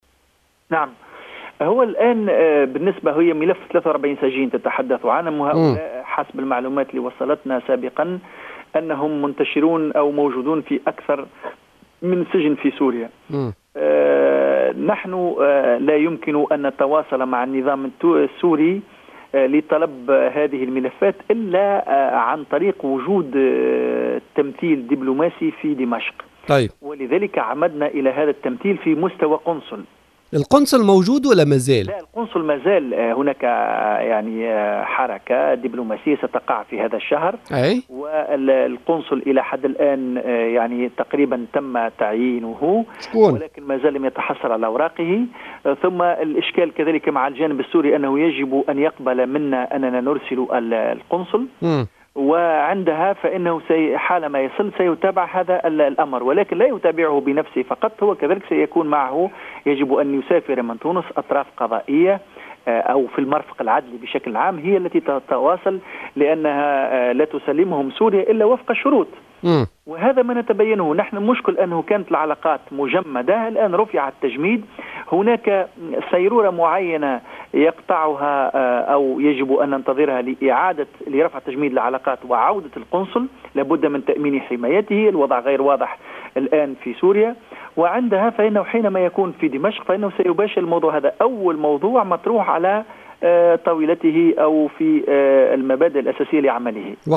أكد التهامي العبدولي، الوزير المكلف بالشؤون العربية والإفريقية، ضيف بوليتيكا اليوم الاثنين، أن القنصل التونسي سيباشر مهامه في دمشق في غضون أيام، وقد تم تعيينه وسيعلن عن ذلك في إطار حركة ديبلوماسية في شهر جوان الحالي، مضيفا أن انطلاق عمله في سوريا مرتبط بموافقة النظام السوري.